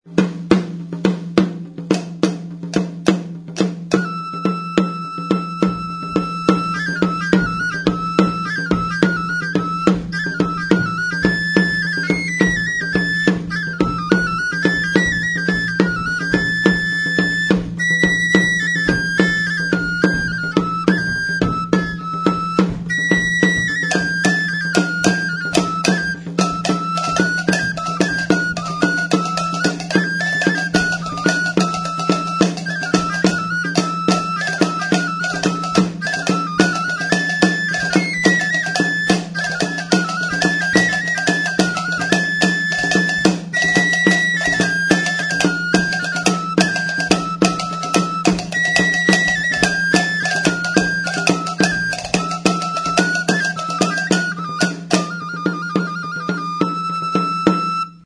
Membranophones -> Frappés -> Frappés à l'aide des baguettes
HM udazkeneko kontzertua.
TAMBOR
Ahuntz larruak dituen zurezko danborra. Normalean Don afintzatzen da.